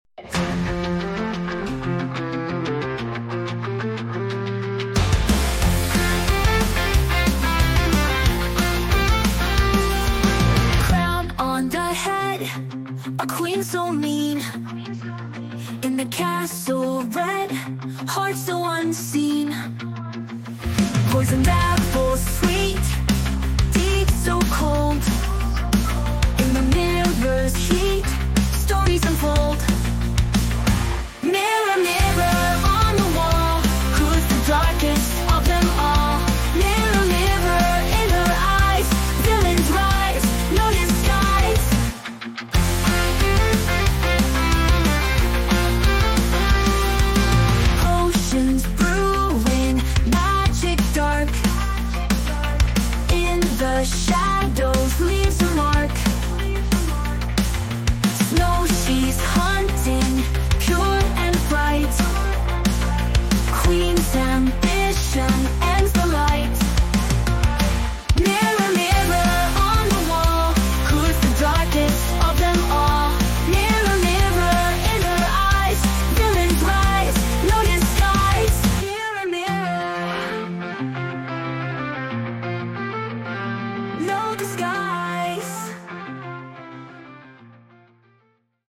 Made With Ai!